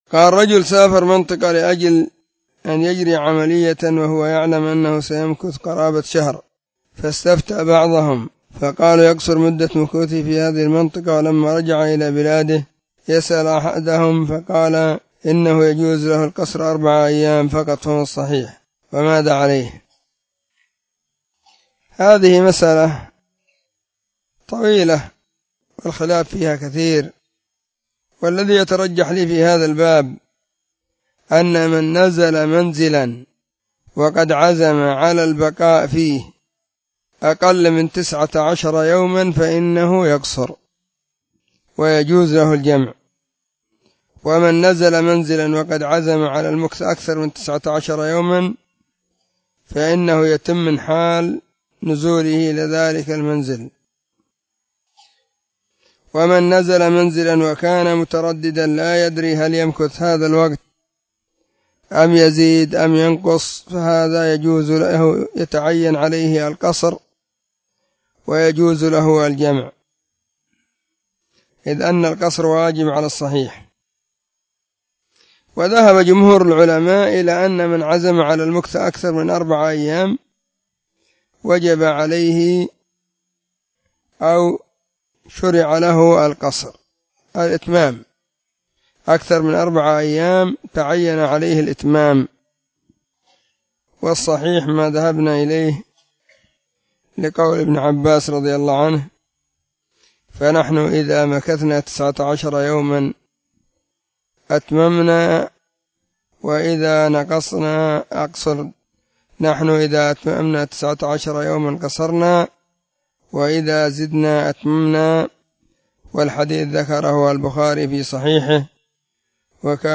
🔹 سلسلة الفتاوى الصوتية 🔸 الإثنين 26 /ربيع الاول/ 1443 هجرية. ⭕ أسئلة ⭕ 2
📢 مسجد الصحابة – بالغيضة – المهرة، اليمن حرسها الله.